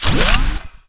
sci-fi_weapon_reload_07.wav